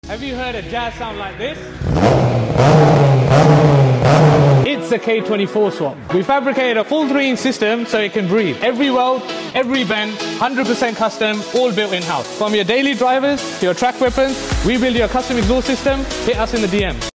HONDA JAZZ K24 CUSTOM EXHAUST! sound effects free download